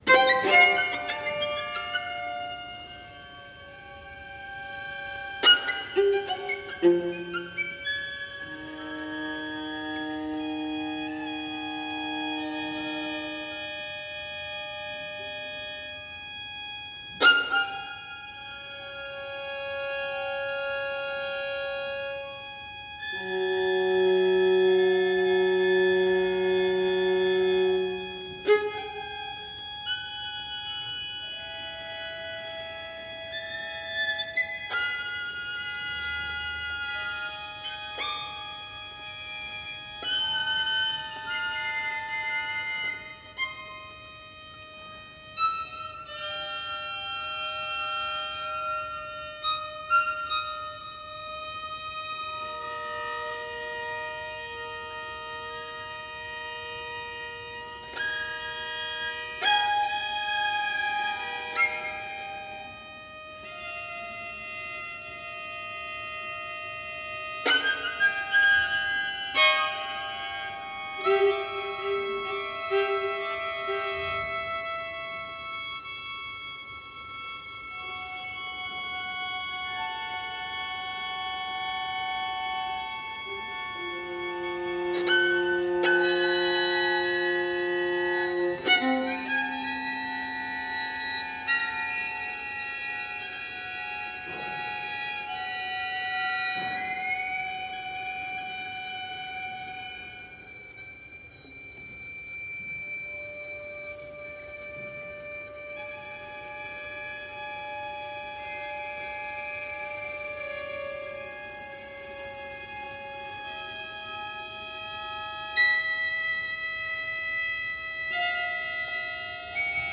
string quartets